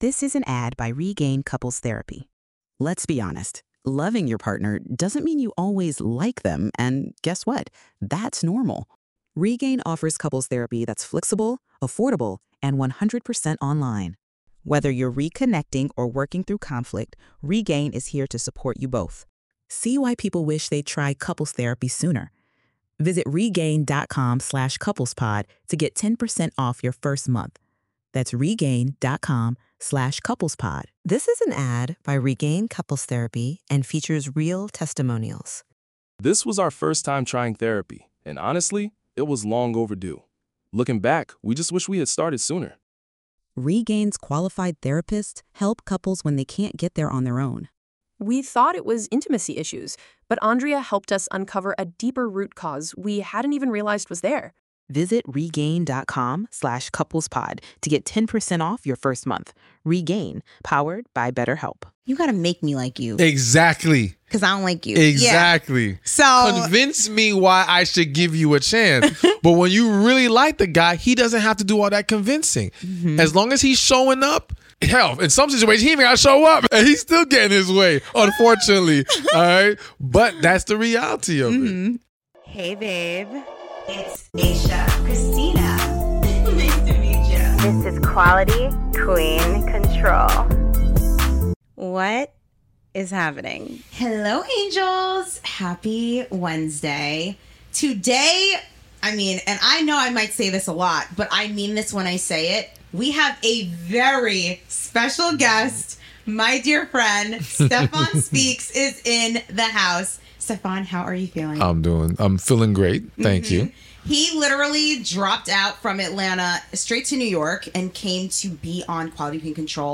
In this Q&A session